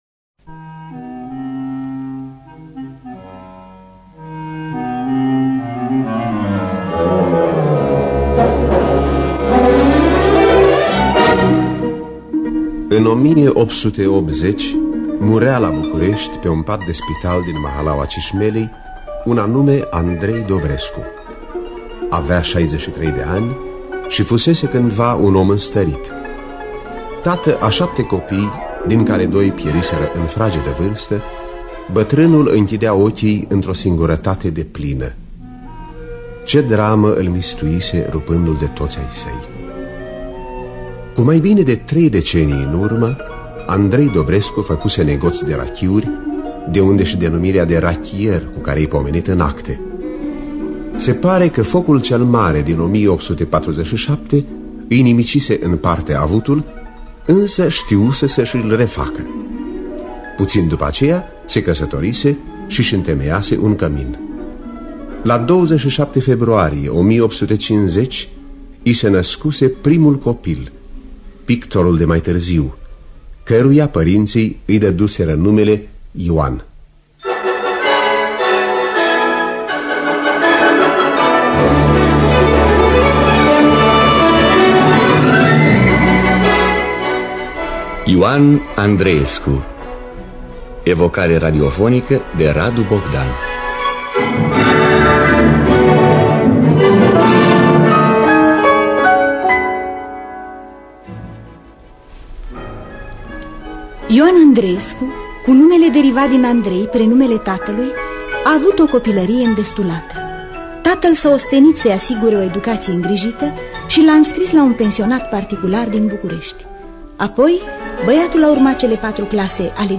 Evocare radiofonică